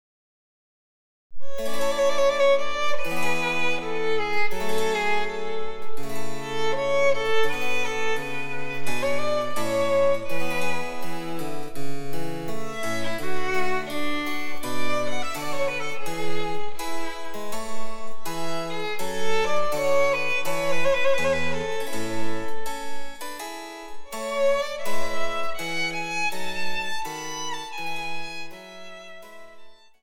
作品１−１４ははつらつとした曲調の名作で、多彩なリズム型を惜しげなく盛り込んでいる点に特徴があります。
第１楽章はアダージョ、４分の４拍子です。
■ヴァイオリンによる演奏